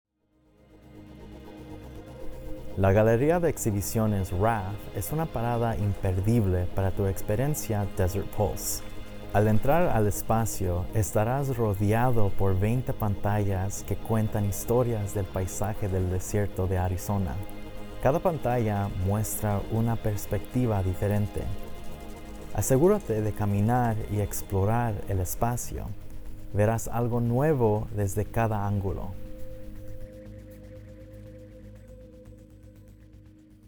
Este es un viaje sonoro autoguiado, diseñado para acompañarte mientras te desplazas entre los sitios de las instalaciones.